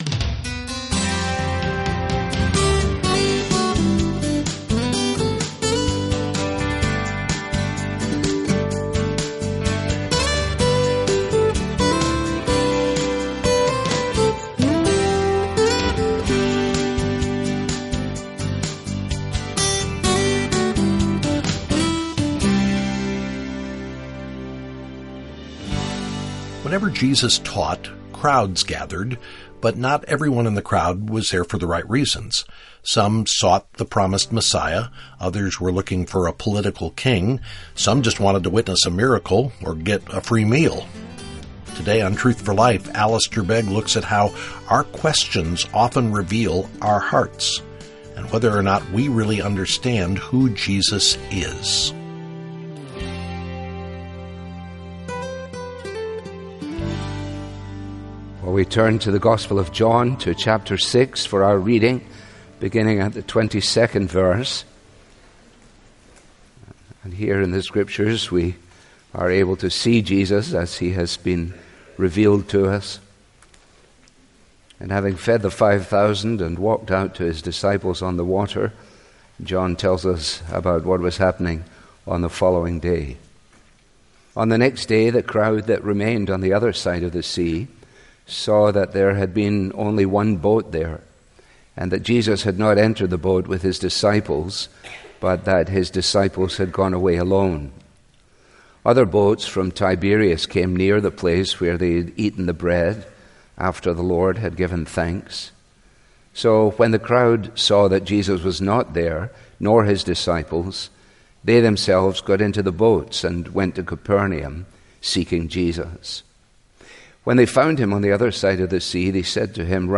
Helpful Resources - Learn about God's salvation plan - Read our most recent articles - Subscribe to our daily devotional Follow Us YouTube | Instagram | Facebook | Twitter This listener-funded program features the clear, relevant Bible teaching of Alistair Begg.